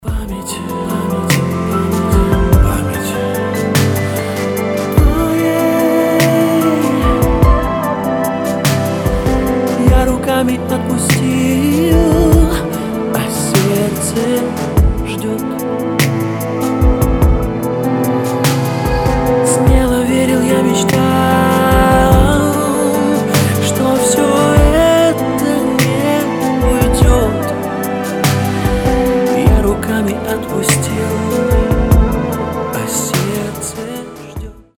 • Качество: 320, Stereo
поп
мужской вокал
грустные
спокойные
романтичные
фортепиано
лиричные